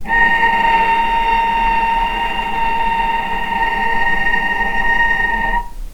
vc-A#5-pp.AIF